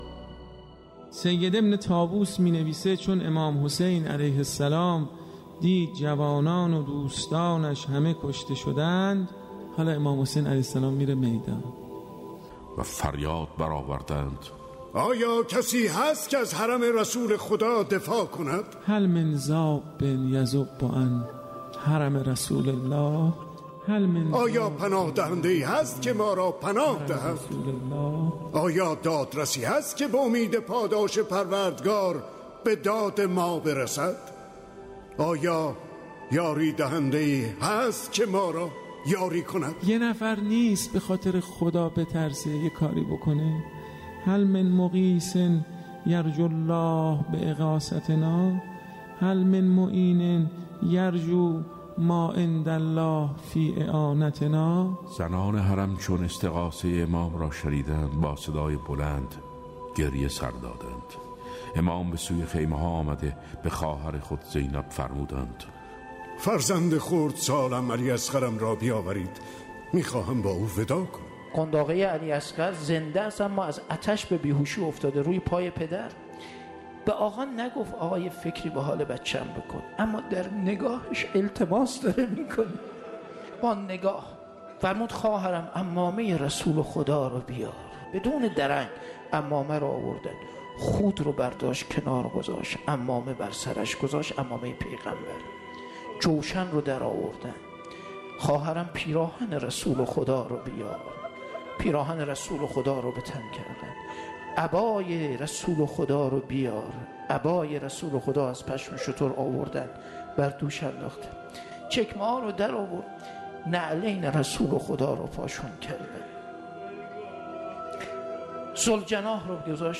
مجموعه ای از روایت خوانی ها و مداحی ها